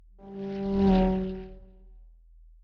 pgs/Assets/Audio/Sci-Fi Sounds/Movement/Fly By 05_2.wav at master
Fly By 05_2.wav